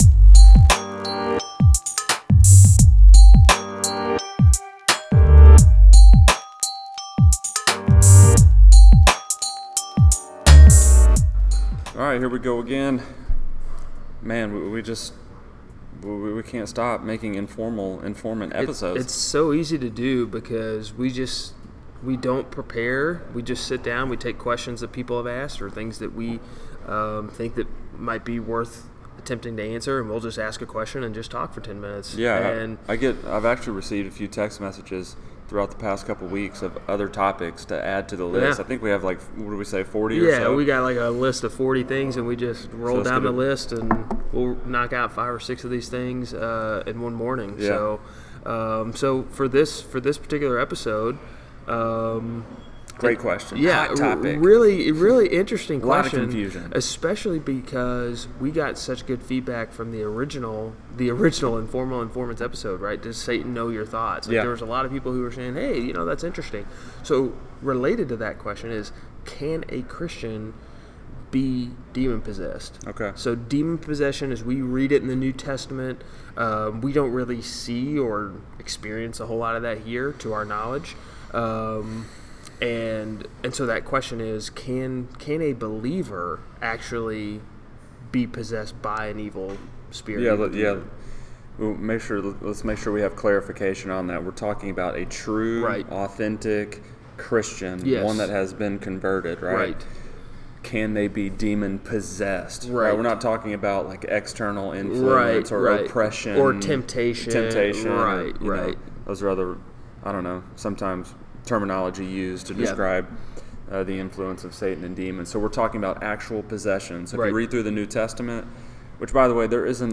The Informants go informal at a local coffee shop to discuss questions and requests from the Infantry.